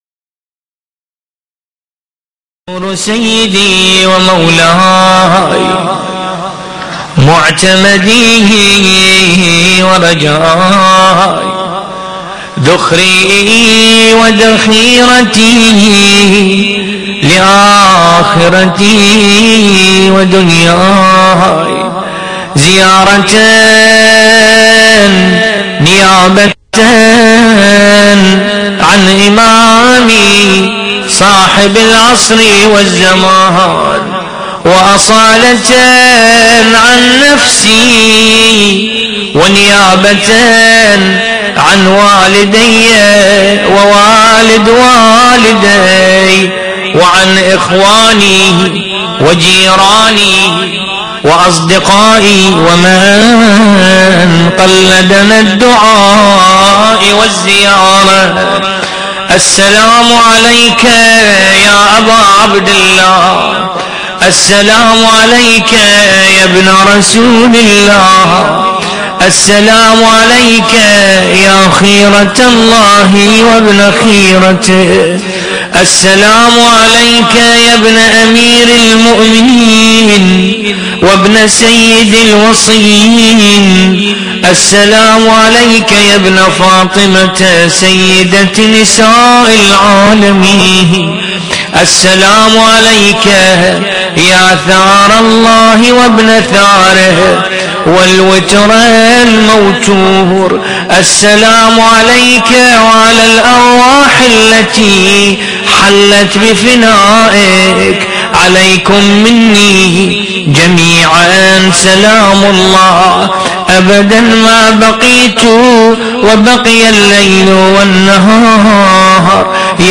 حزينة